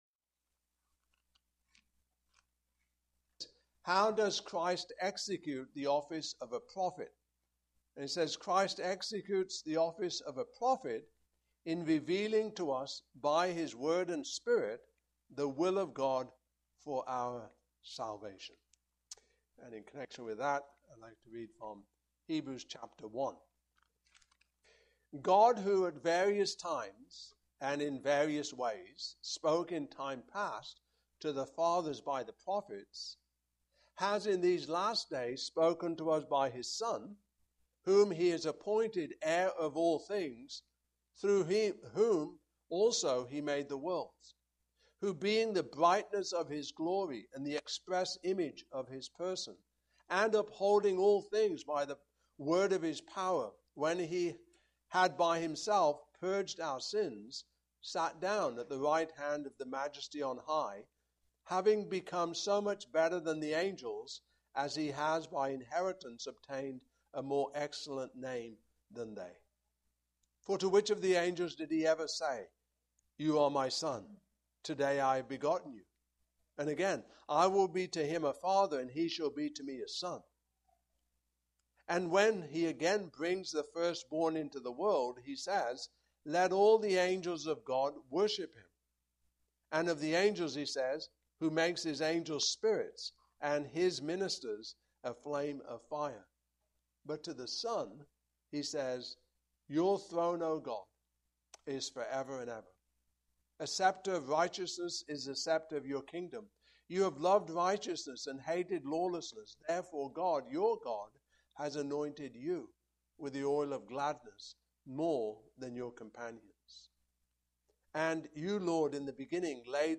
Hebrews 1:1-2:4 Service Type: Evening Service Topics